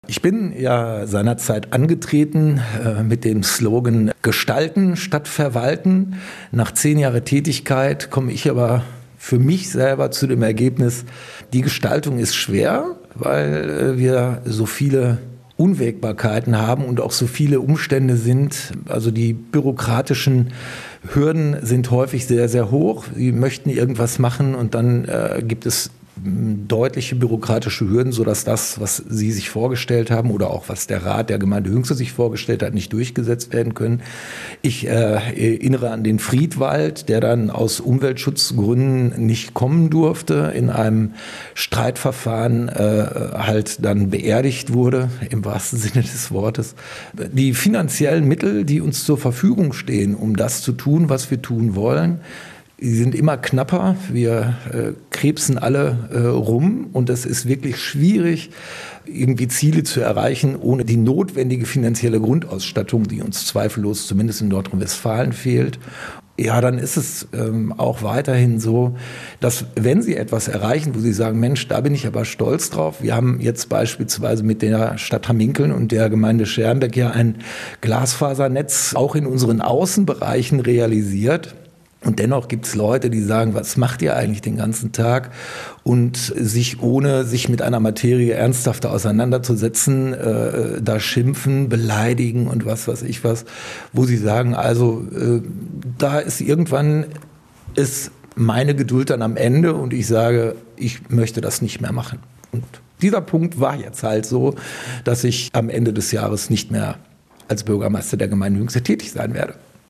statement-bm-dirk-buschmann.mp3